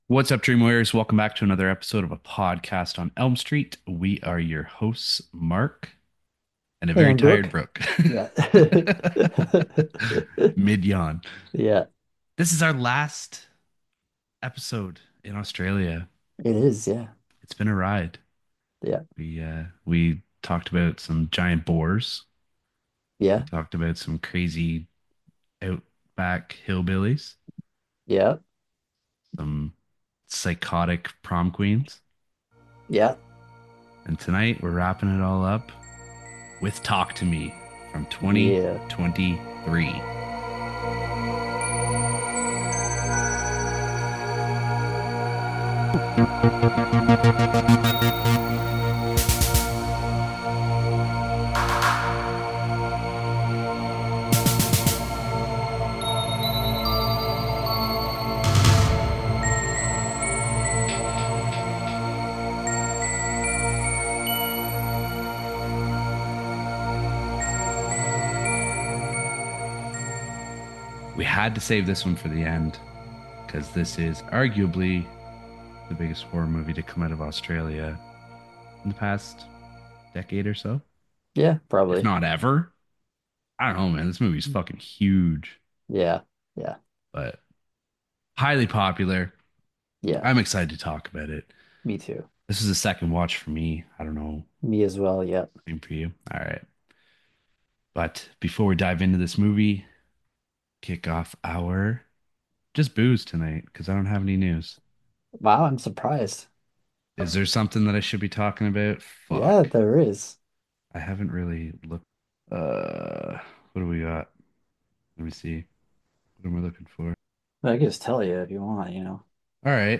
A podcast discussing horror movies from 2 Canadian horror movie fans. Every week we get together and discuss a different movie in length.